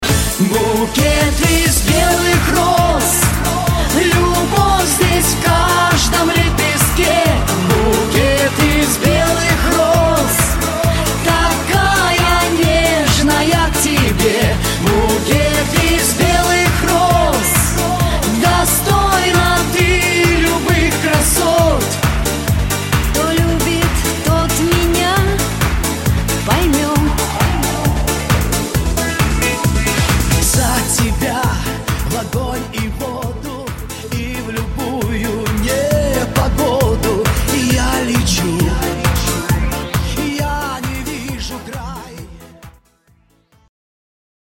шансон рингтоны СКАЧАТЬ РИНГТОН